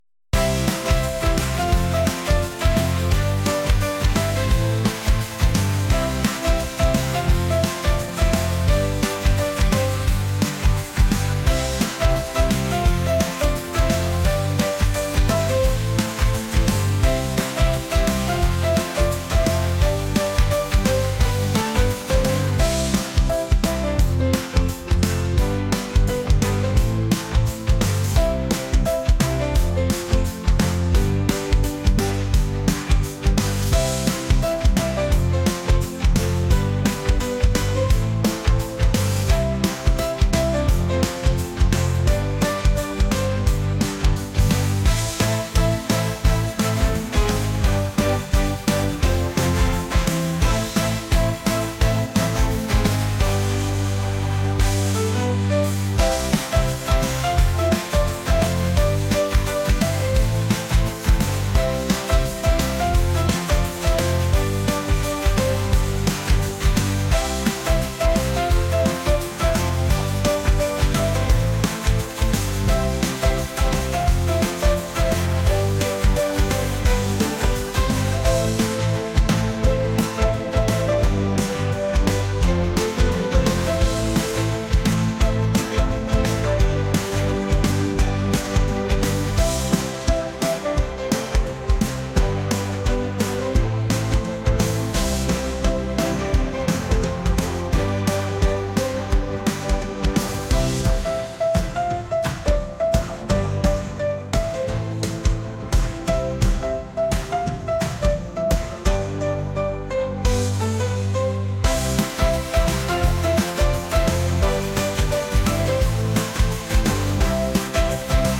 pop | acoustic | laid-back